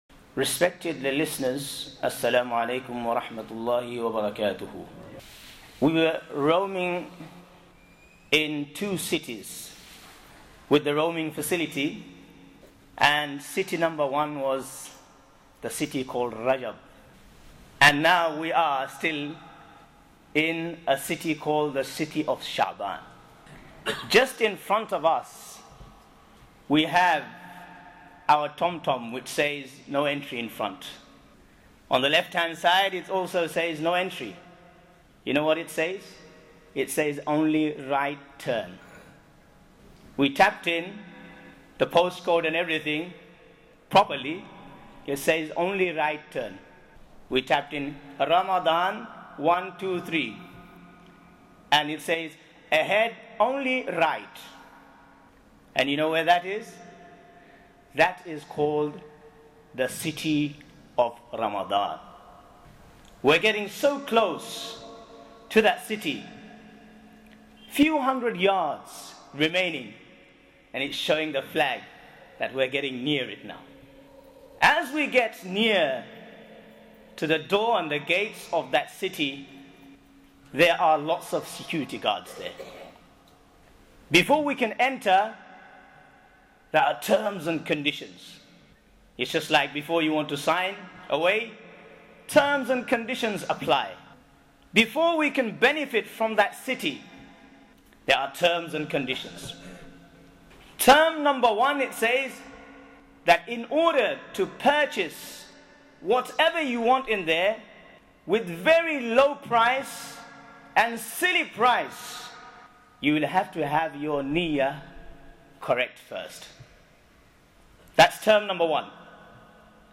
Insha Allah these series of audio lecture are of benefit and provide guidance during this blessed month that is to soon approach us.